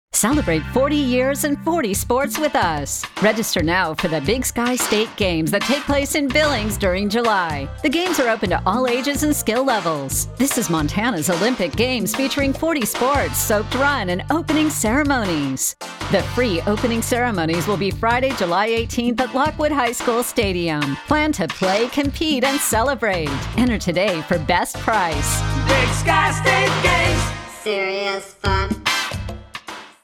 Big-Sky-State-Games-2025-BSSG-Register-Generic-RADIO.mp3